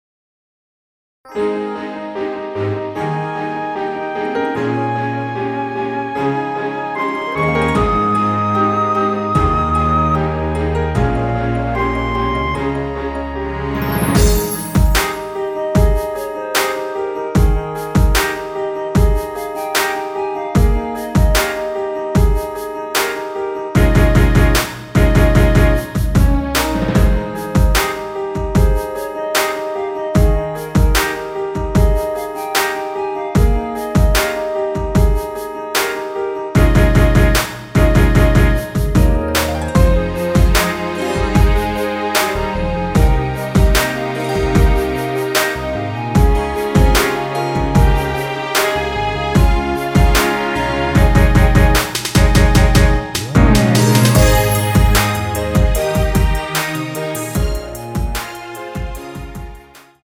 원키 멜로디 포함된 MR입니다.
Ab
앞부분30초, 뒷부분30초씩 편집해서 올려 드리고 있습니다.